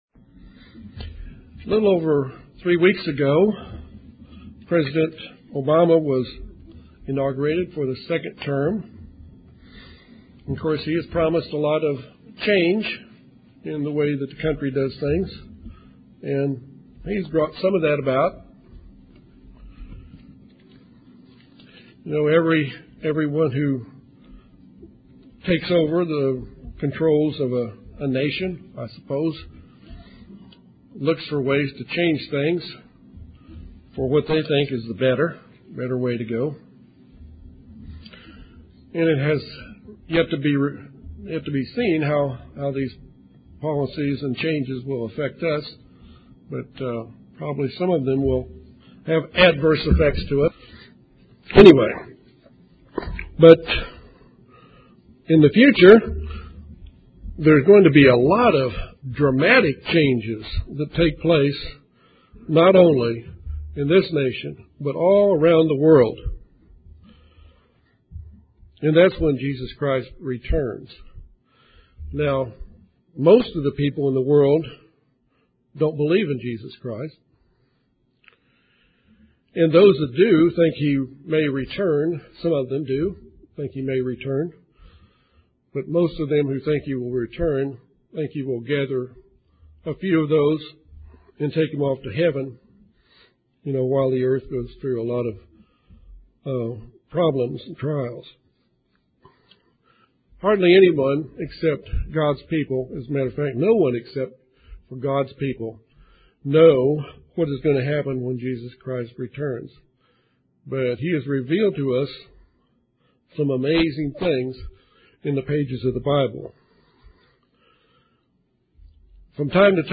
Given in Little Rock, AR Jonesboro, AR
UCG Sermon Studying the bible?